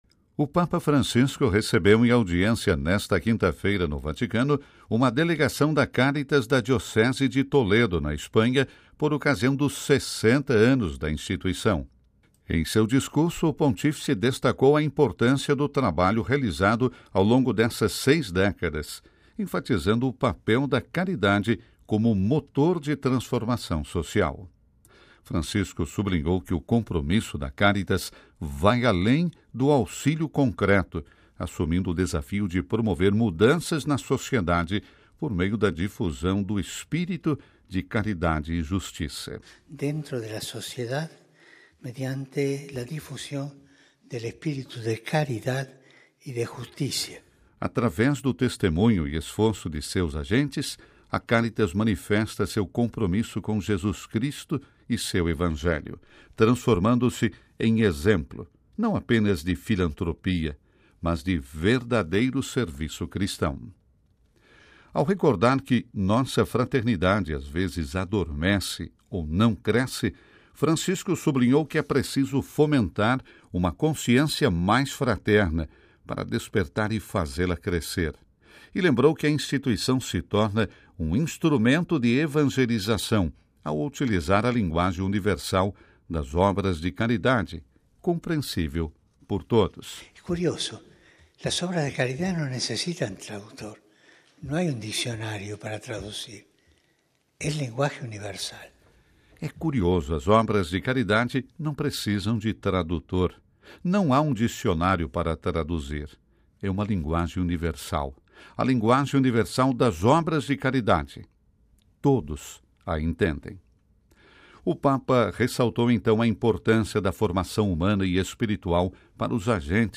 Ouça com a voz do Papa e compartilhe